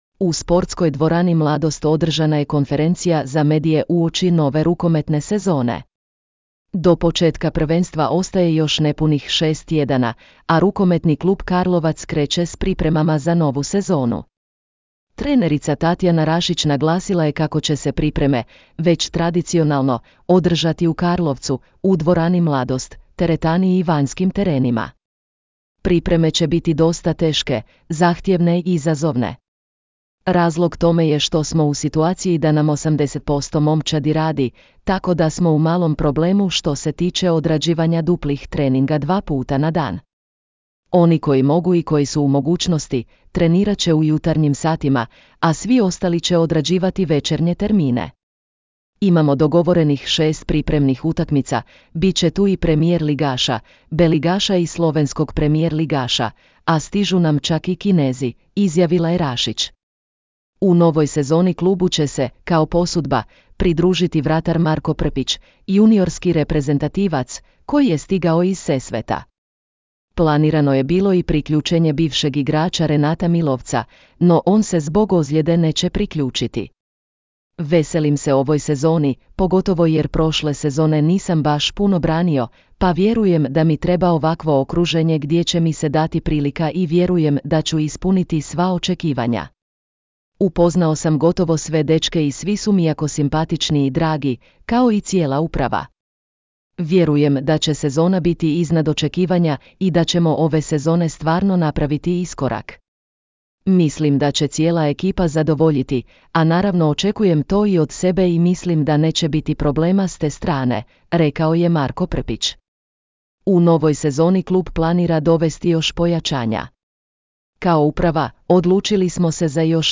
U sportskoj dvorani Mladost održana je konferencija za medije uoči nove rukometne sezone.